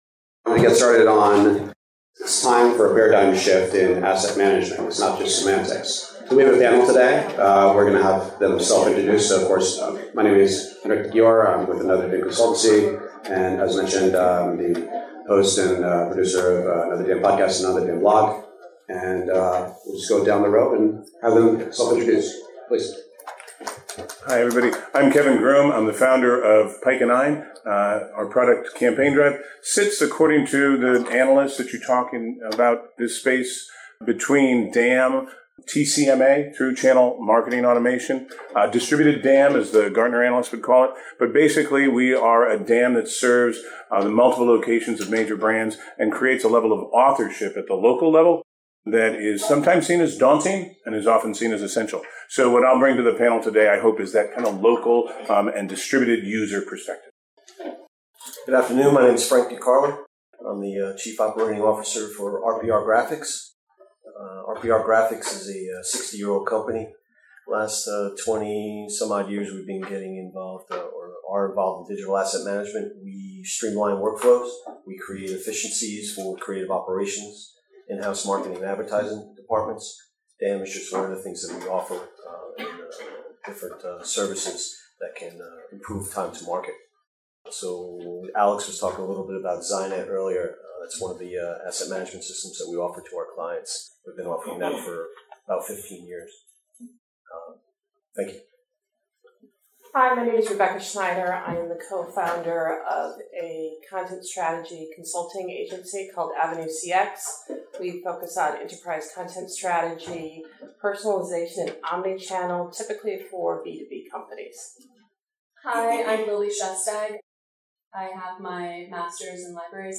panel discussion
In case you missed this NYC DAM Meetup or want to review this discussion again, below is the unedited audio recording of this panel discussion (Duration: 92 minutes)